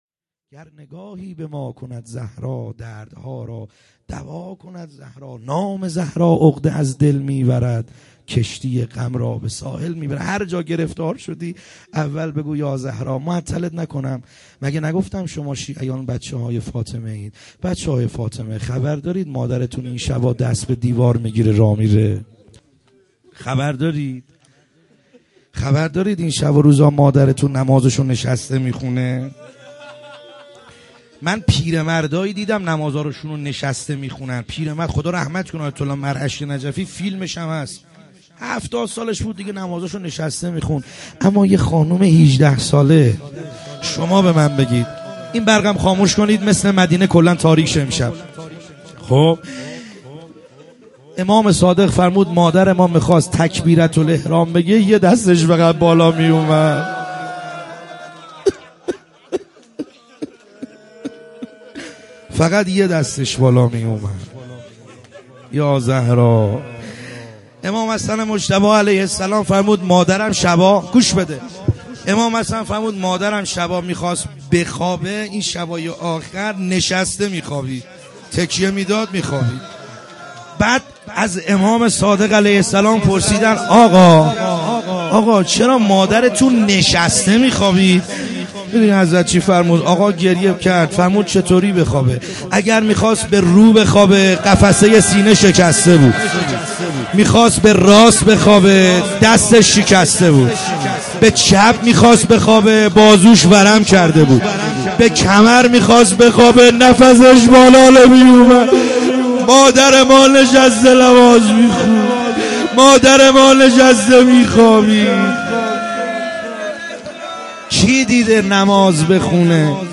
خیمه گاه - بیرق معظم محبین حضرت صاحب الزمان(عج) - روضه